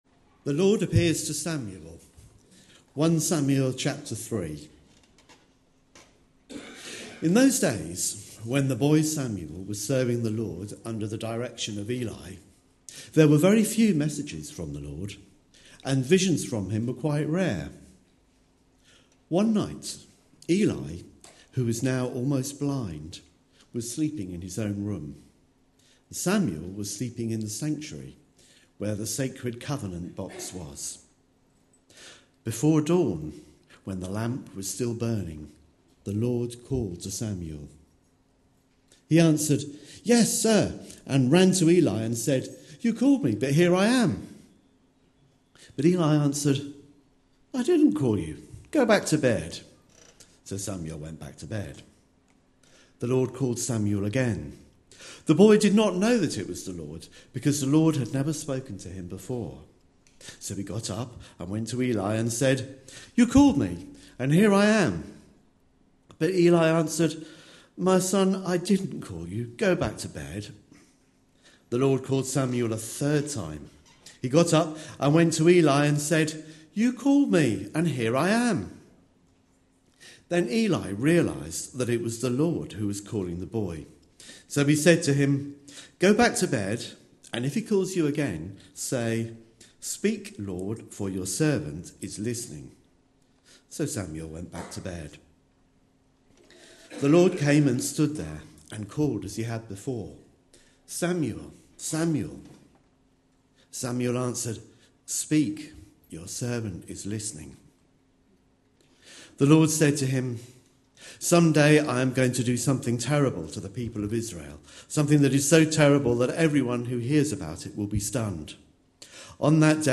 A sermon preached on 18th January, 2015.
A sermon preached on 18th January, 2015. 1 Samuel 3 Listen online Details Reading is 1 Samuel 3, followed by an illustrated talk from a family service. The sons of Eli are described in 1 Samuel 2:12-17, and the talk includes references to Hebrews 1:1-2a and John 14:16-17.